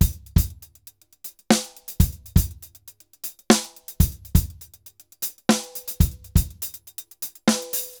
Drums_Candombe 120_1.wav